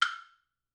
1-woodblock.wav